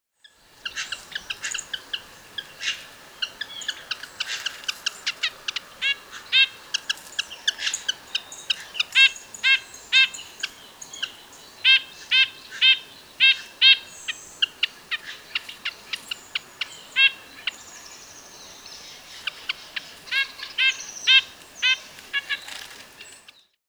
Звуки поползня
Подборка включает разные варианты голосов, записанных в естественной среде обитания.